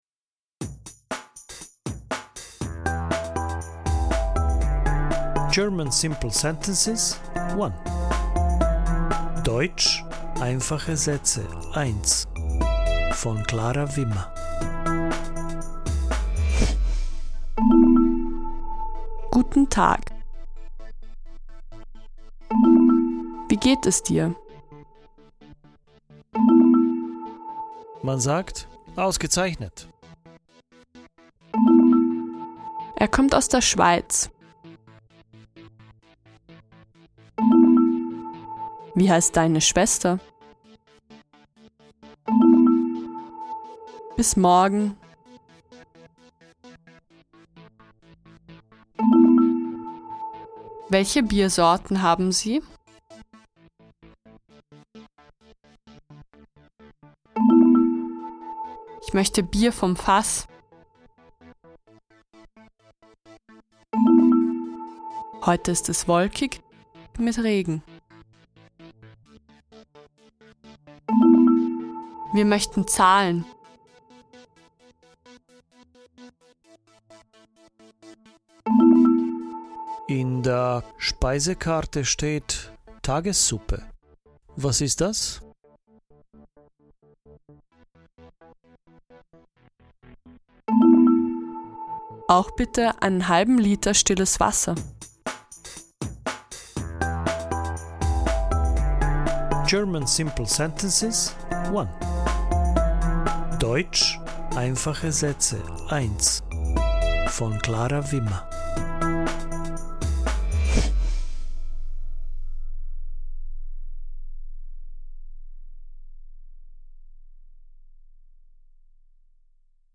The short listening texts offer ready-made sentences to practise and learn the German language effortlessly.
The sentences are presented in English first, followed by the German version and an inviting pause to repeat the sentence in German.